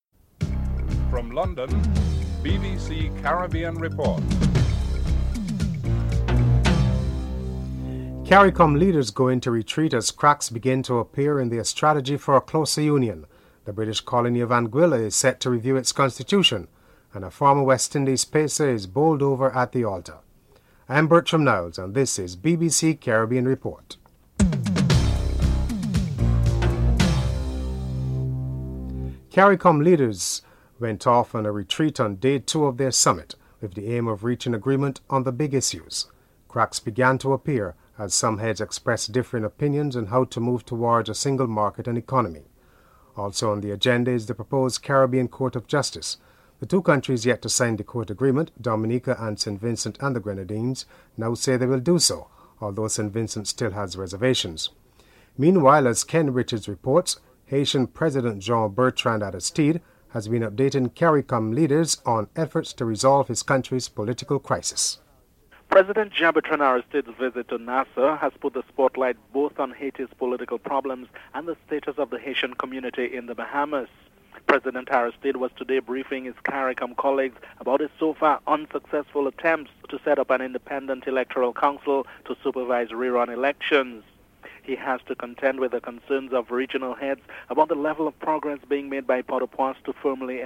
Transport and Works Minister Dr Peter Phillips is interviewed (10:44-14:53)